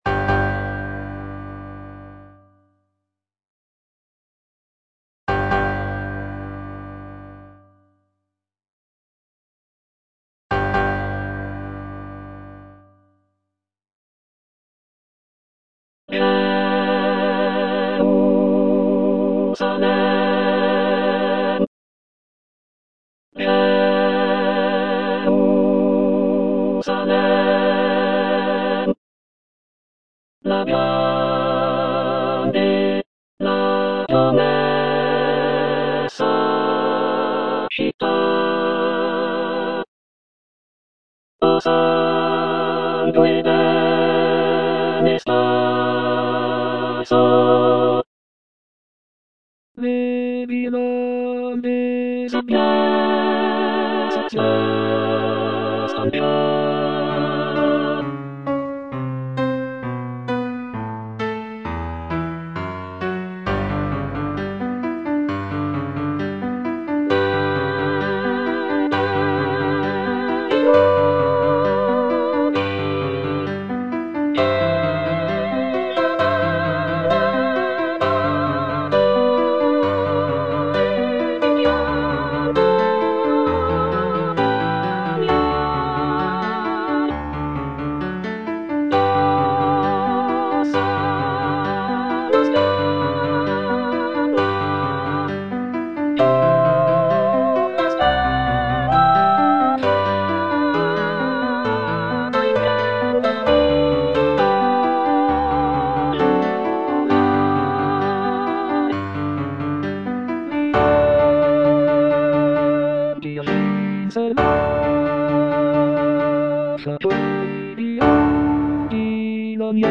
The music captures the solemn and majestic procession of the Lombard Crusaders as they embark on their journey to the Holy Land. The powerful and emotional choral writing, along with the grand orchestration, creates a sense of reverence and anticipation for the epic quest ahead.